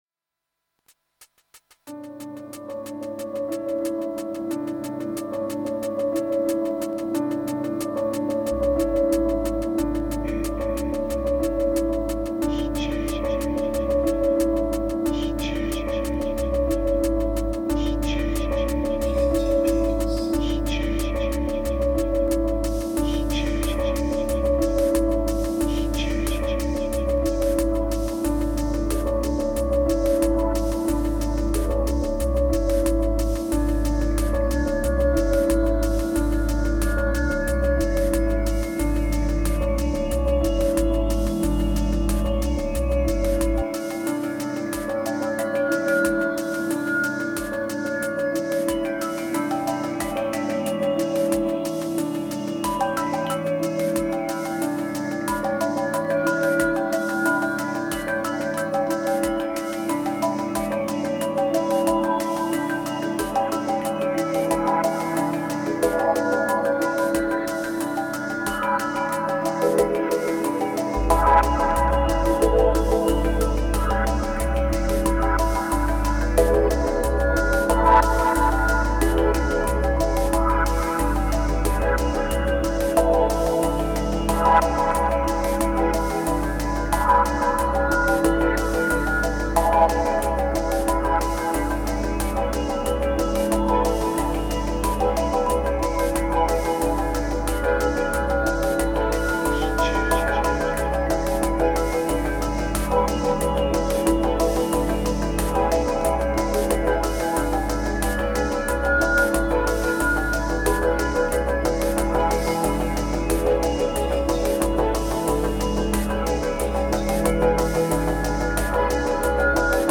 914📈 - 92%🤔 - 91BPM🔊 - 2024-08-30📅 - 739🌟
Very warm session in the dark.